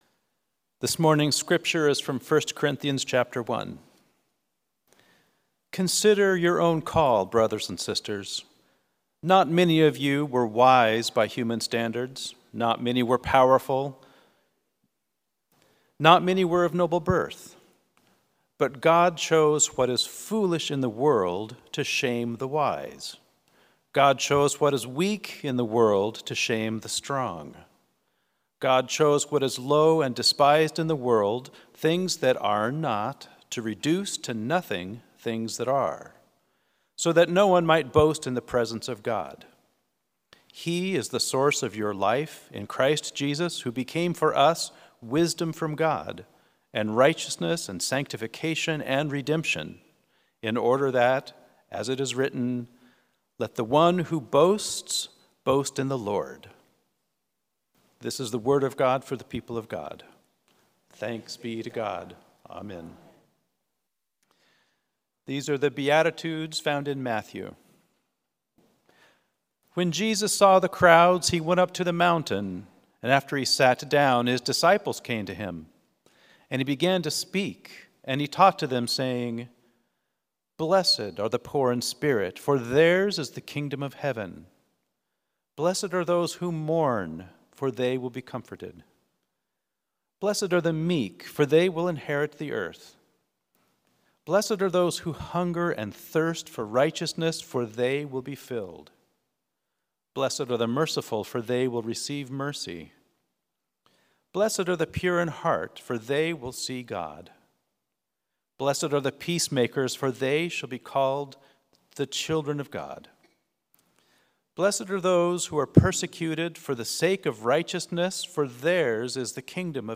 Sermon – Methodist Church Riverside
Fourth Sunday after the Epiphany sermon